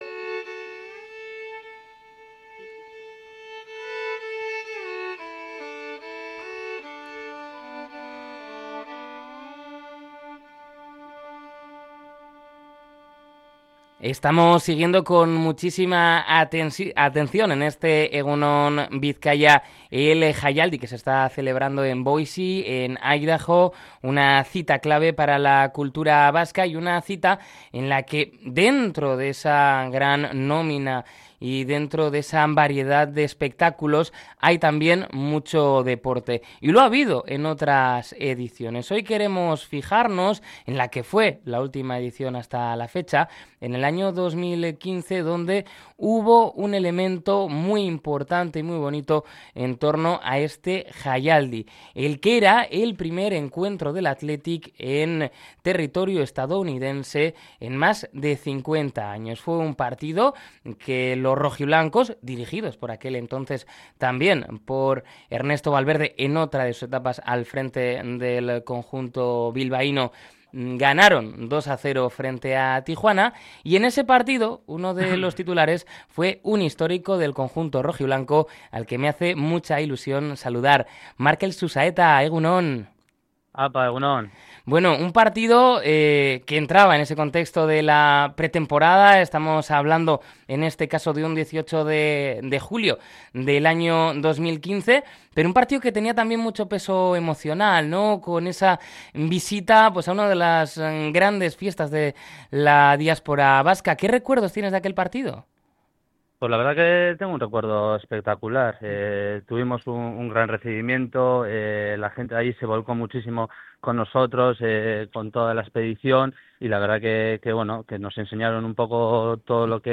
El de Eibar, ha rememorado aquella experiencia en nuestro programa especial EgunOn Jaialdi.